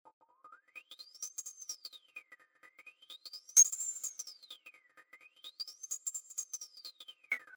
■　2×2 Pole ローパス・フィルターのカットオフをオートメーションさせたハイハット・トラック。Inertiaは0%に設定。
Inertia_low.mp3